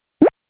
bleep.au